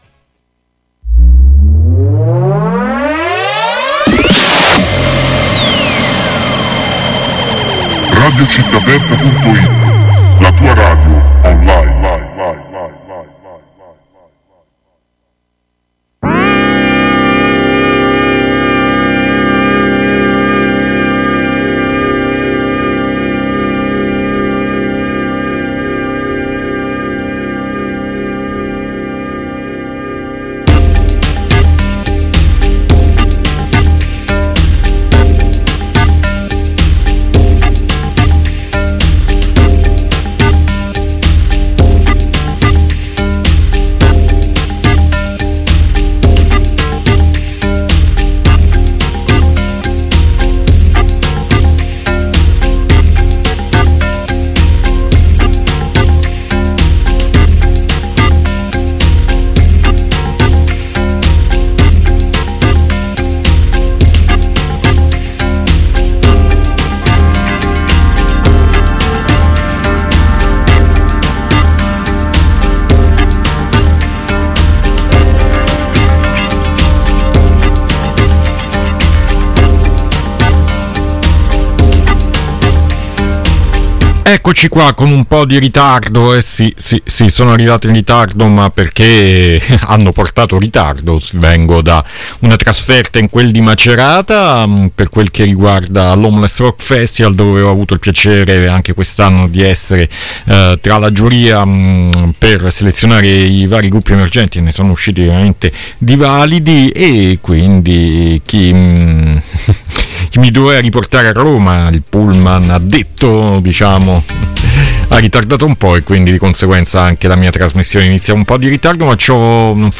C'è stato un bel percorso musicale in ambito orientale con formazioni cinesi e coreane che danno prova di grande sensibilità post rock.